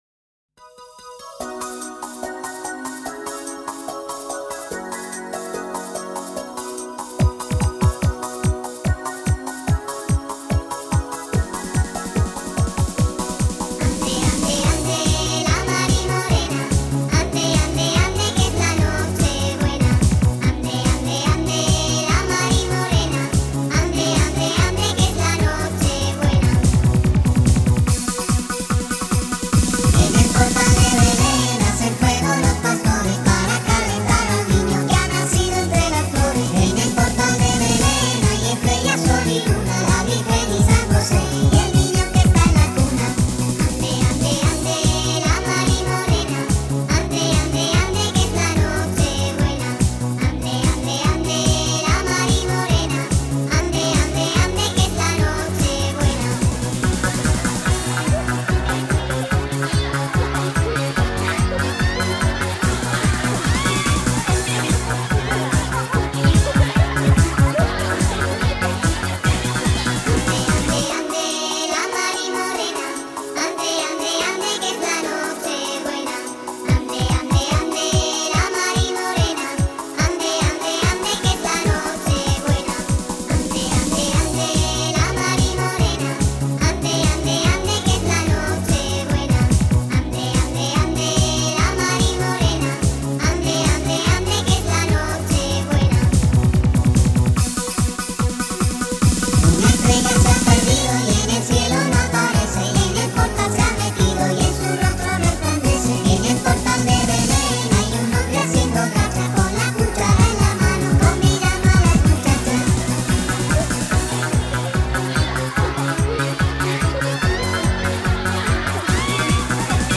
Christmas carols in Spanish